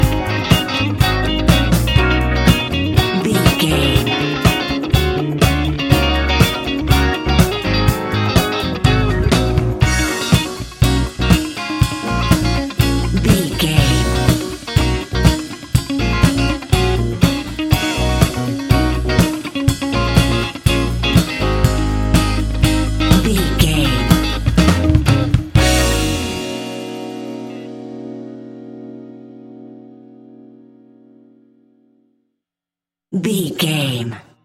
Ionian/Major
A♯
house
synths
techno
trance
instrumentals